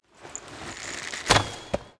archer_powershot.wav